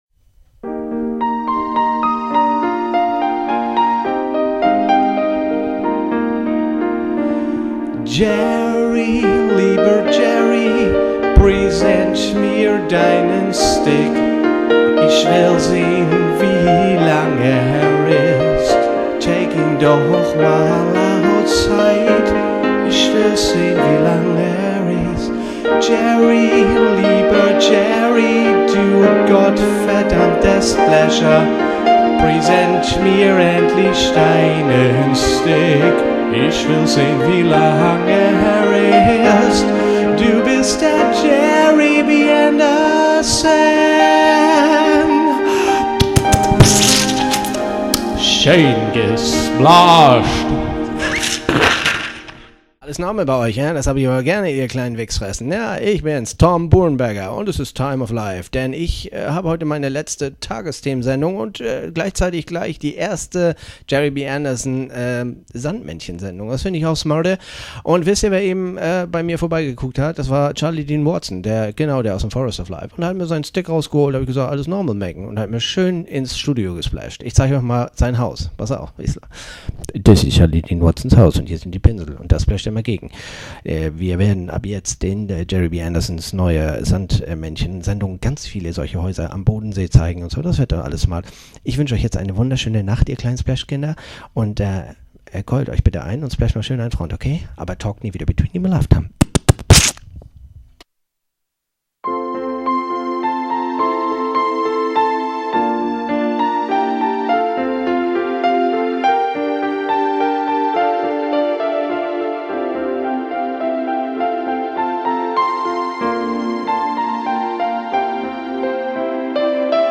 Tom Buhrow moderiert _Das Sandmännchen_ (192kbit_Opus).ogg